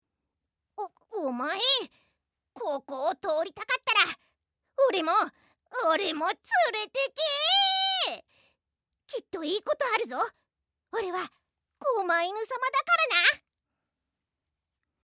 人外　犬っぽいわんぱく